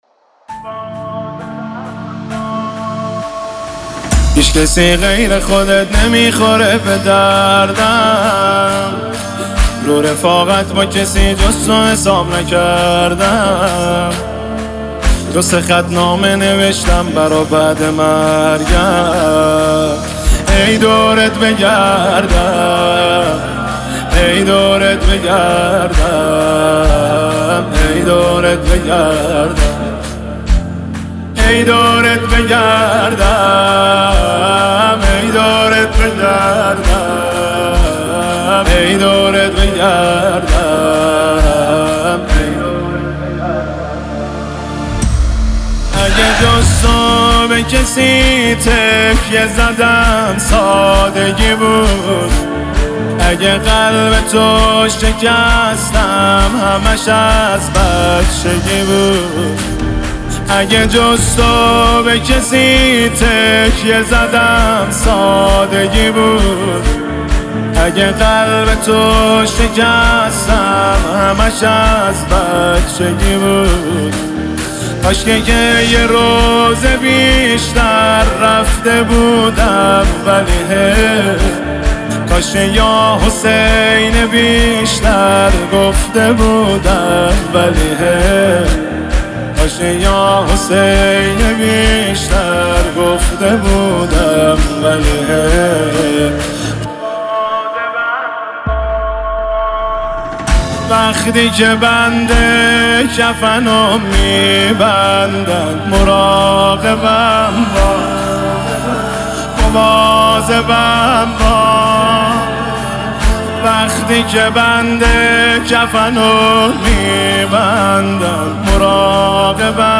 مناجاتی عاشقانه با امام حسین علیه السلام